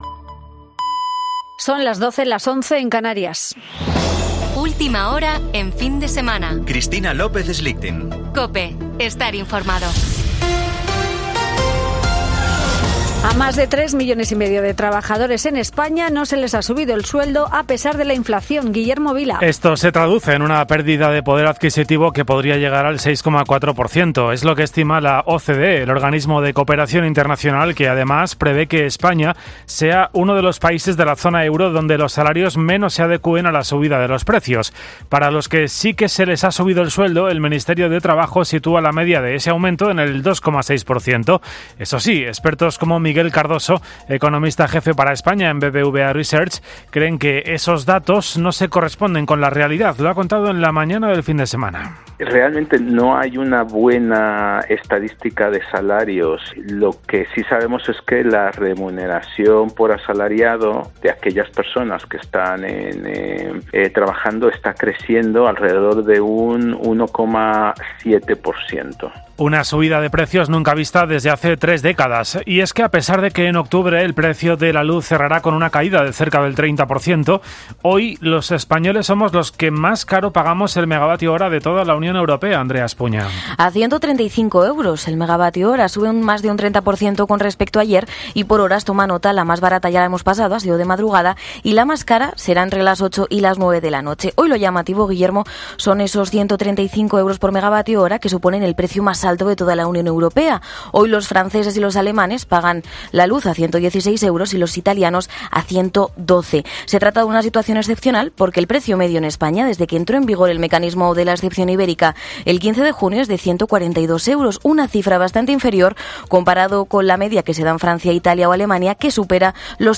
Boletín de noticias de COPE del 30 de octubre de 2022 a las 12.00 horas